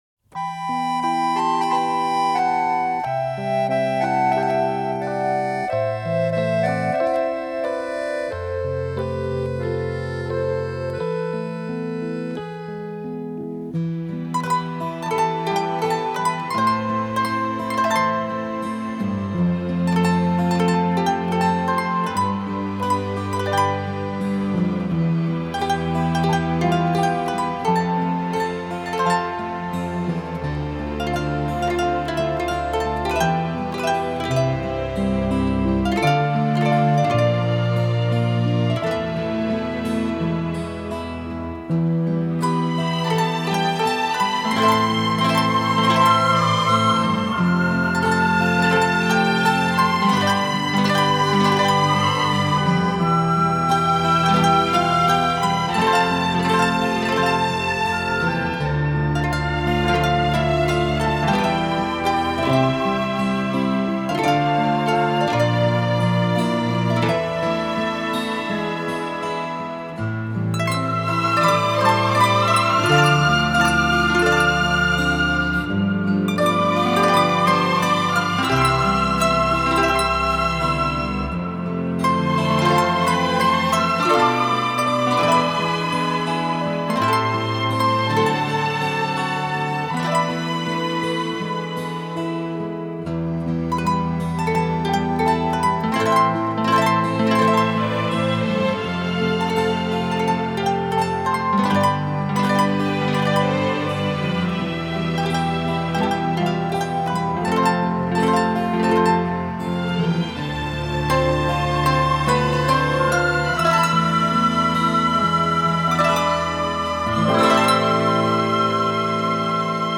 GENRE :New Age